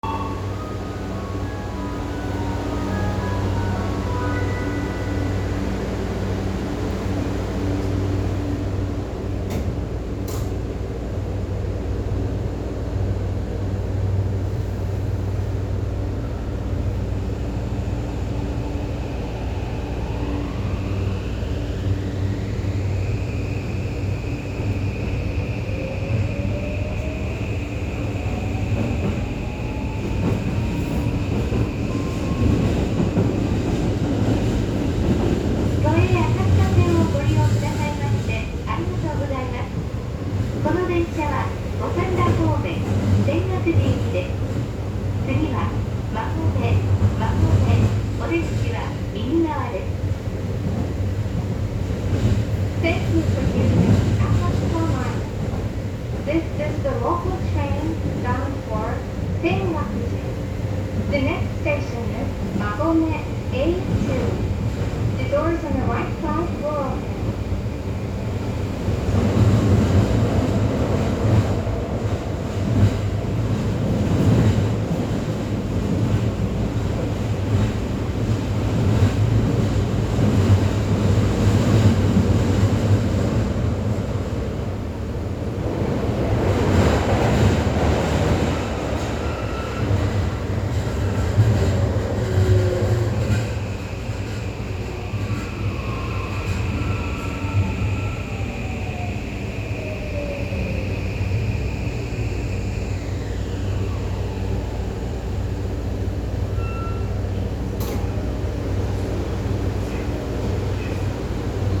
・5500形走行音
自動放送が搭載されているのは5300形と同様ですが、音質がとてもクリアなものとなった為聞こえやすくなりました。走行装置は三菱SiCのVVVF。なかなか特徴的な音で、近年の新型車としてはかなり個性が出ている部類なのではないでしょうか。